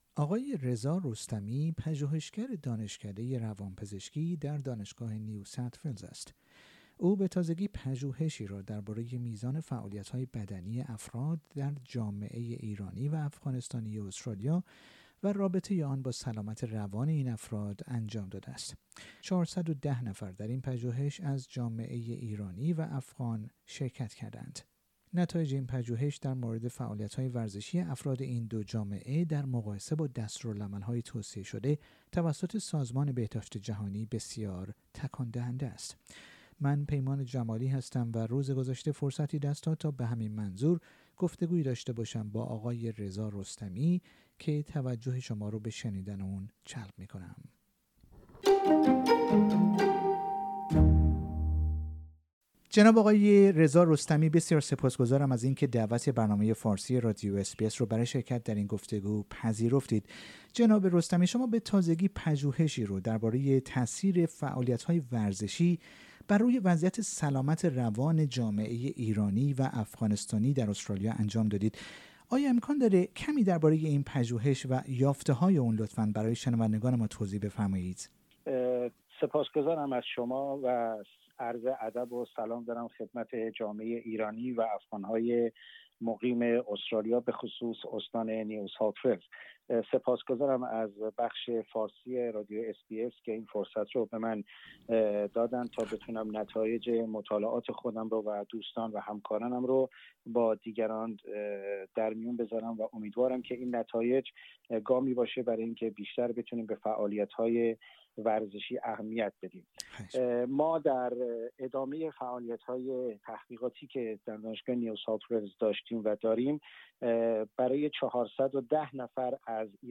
گفتگویی